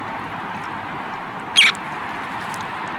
Semipalmated Plover
Chorlito Semipalmeado,
Charadrius semipalmatus
VOZ: Un silbido prolongado.